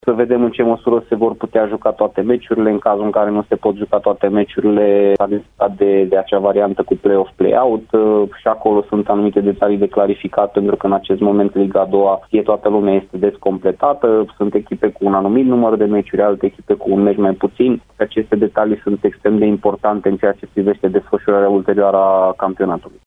Cei doi tehnicieni, invitați azi la Arena Radio, au vorbit și despre stările de spirit ale loturilor pe care le conduc de la distanță: